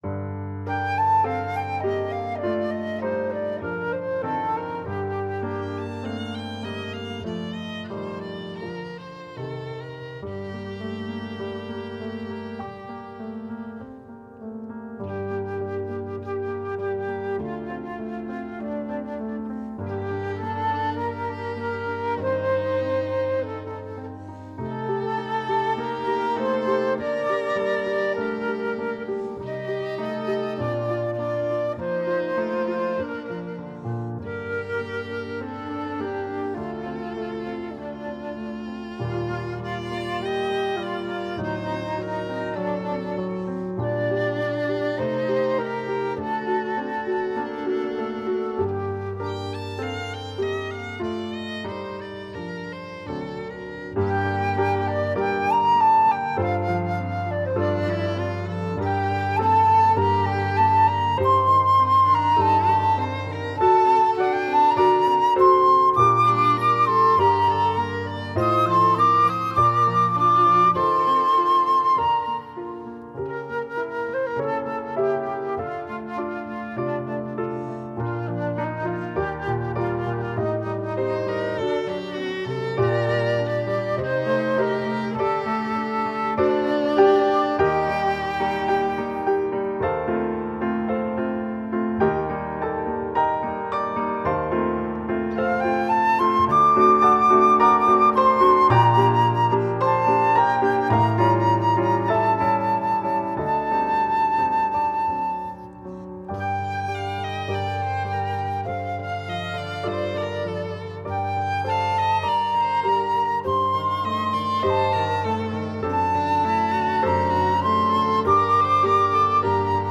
Voicing: Bb/C Instr